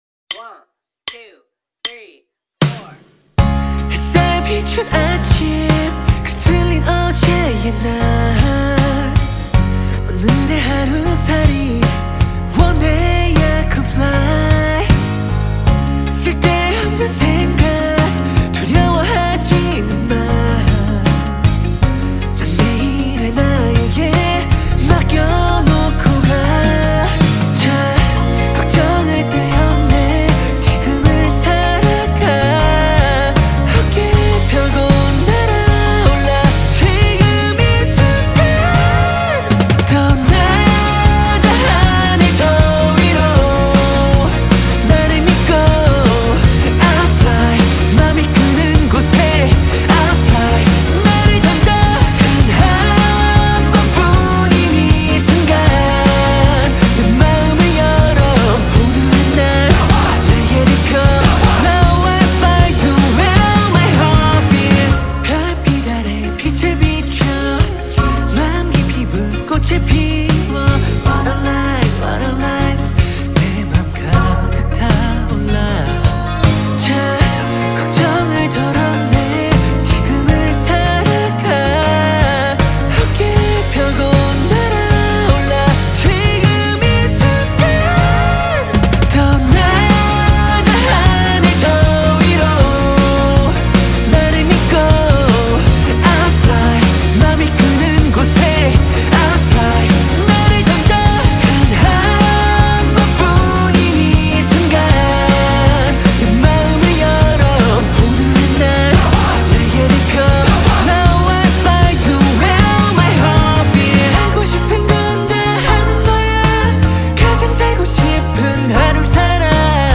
کی پاپ